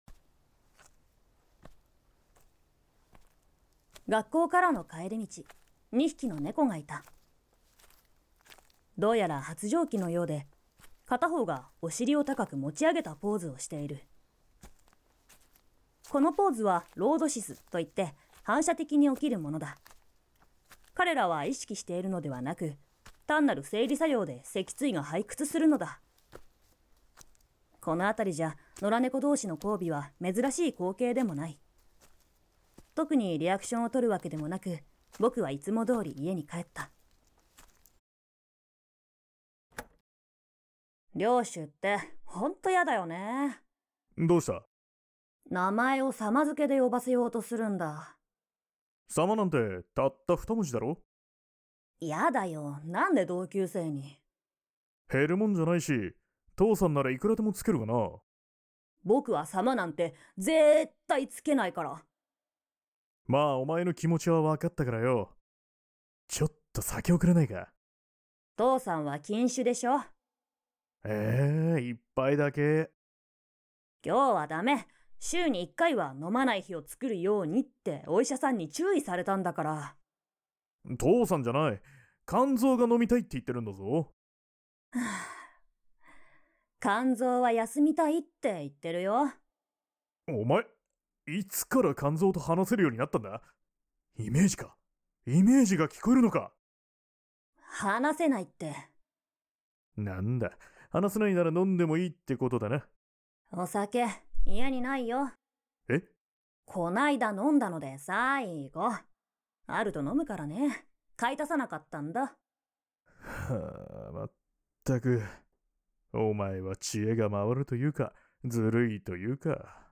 搞笑 喜剧